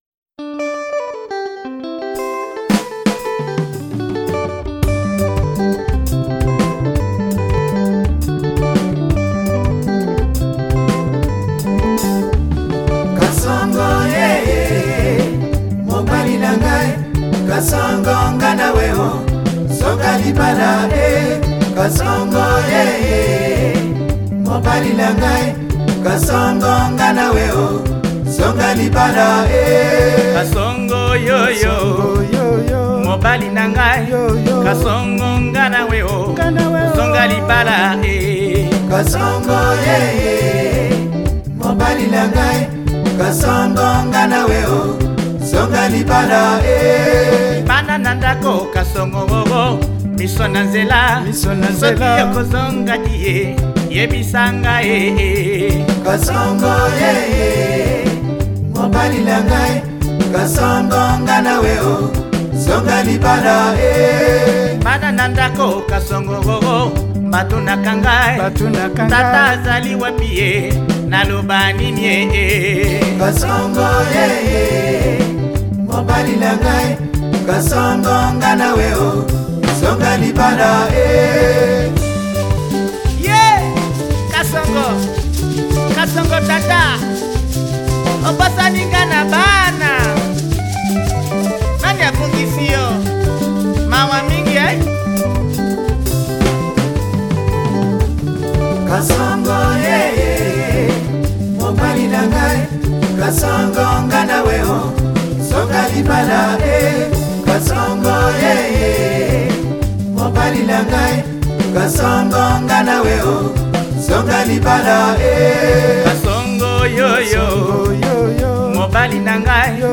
• Genre: Afrobeat